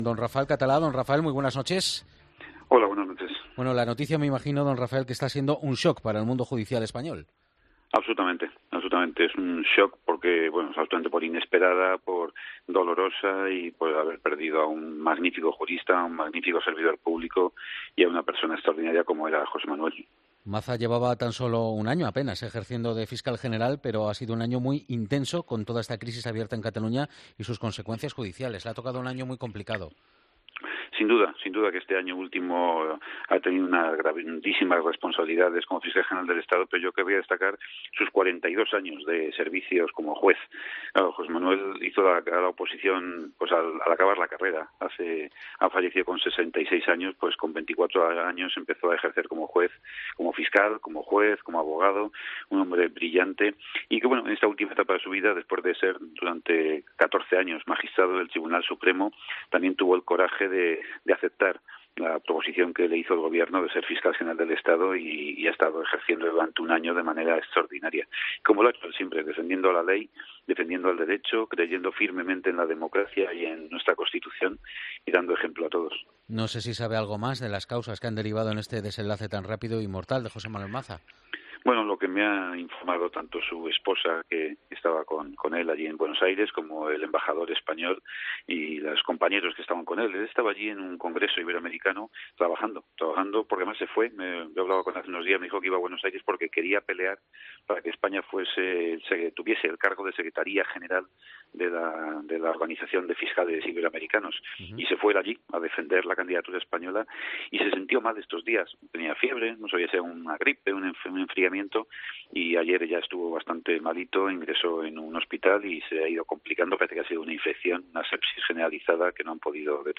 Escucha la entrevista completa a Rafael Catalá en 'La Linterna'